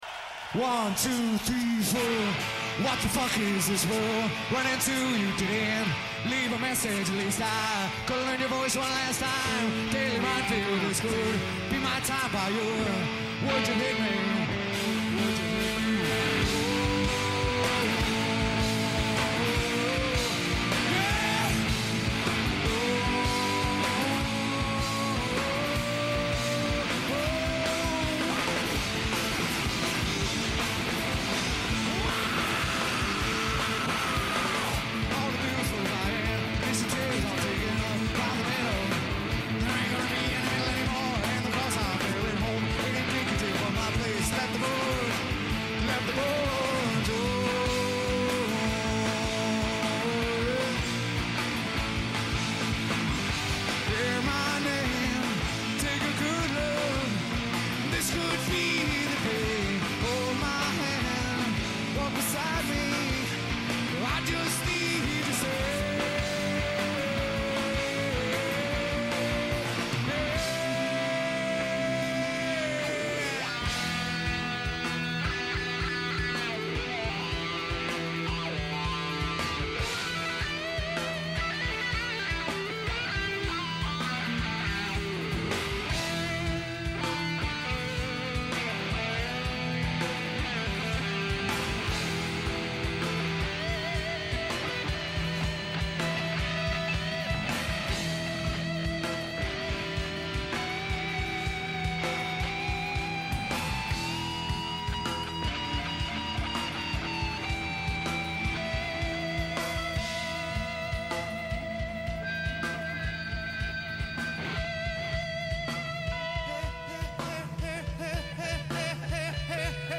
Tool Live